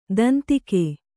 ♪ dantike